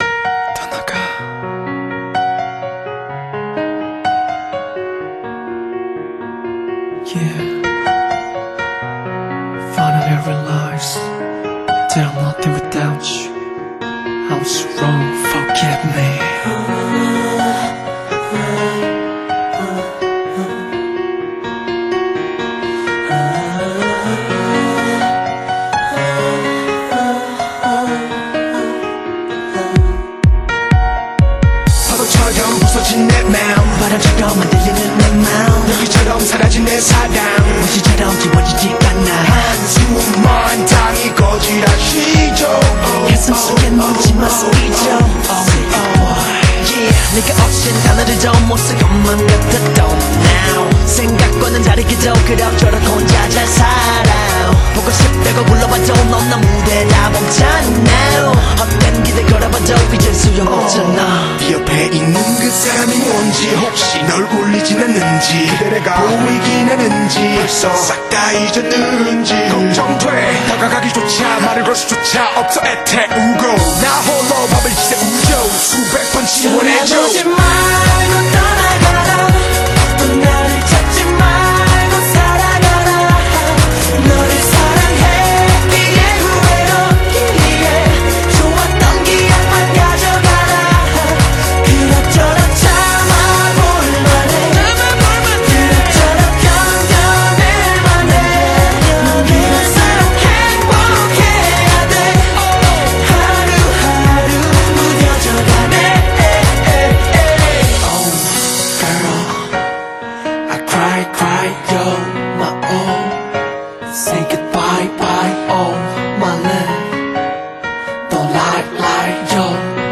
BPM63-126
Audio QualityMusic Cut
評論A classic K-Pop song from 2008.